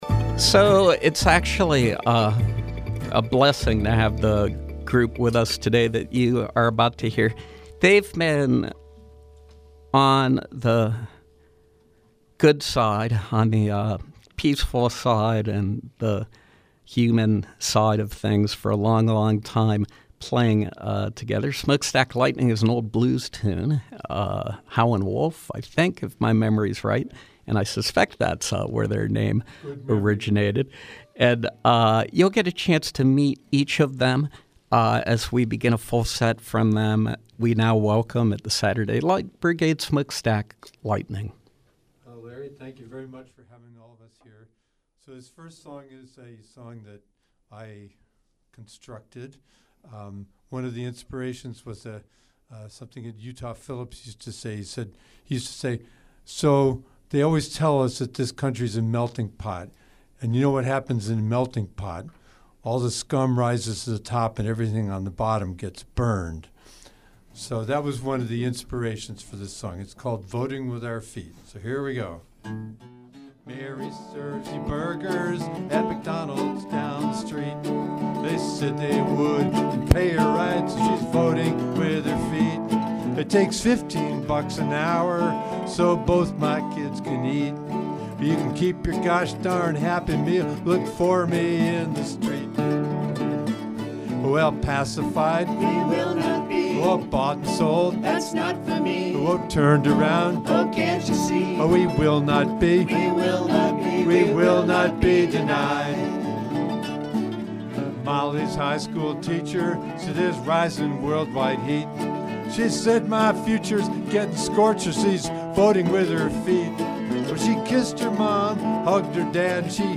Live music with group